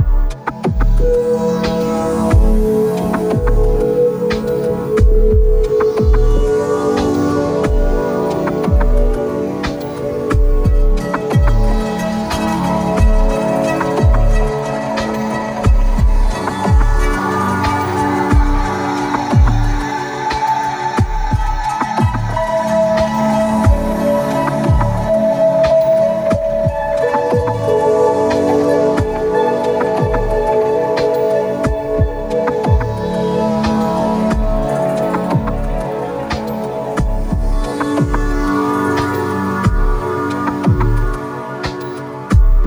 Exploring Ambient Spaces + Binaural Beats = Sound Exploration Thread
Here's a little groovy loop in which I embedded some Theta Binaural tones into (made in Drambo of course).
Theta Drone : Drambo (Oscillator Mini Tuned to 130+136 Hz) Hard Panned L/R+Mix